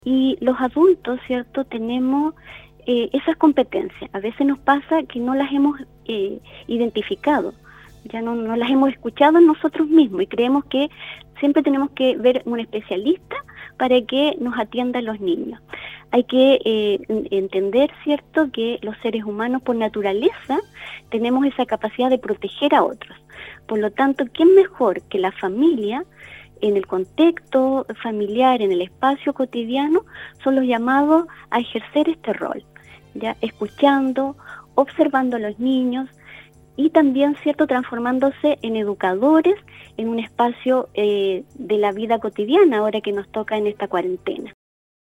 La mañana de este miércoles, y como parte de un aporte a la comunidad atacameña, por el mes de abril se estará realizando una entrevista con el equipo de la Fundación Integra Atacama donde se tocarán temas fundamentales para el desarrollo de los niños y niñas atacameños.